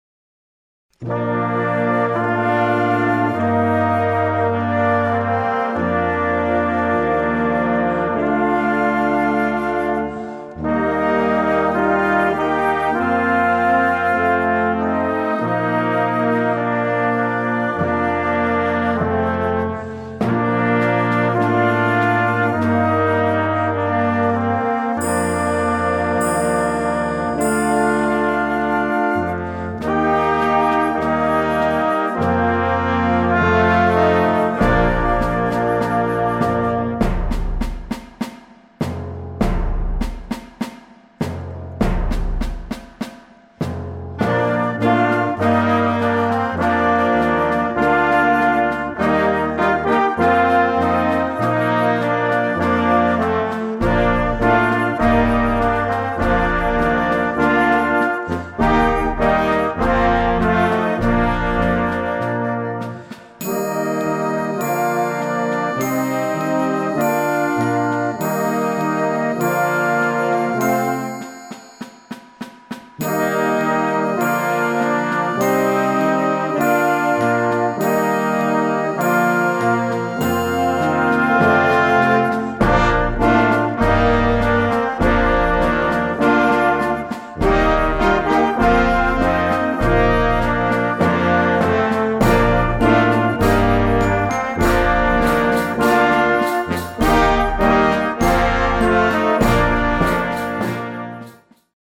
Category Concert/wind/brass band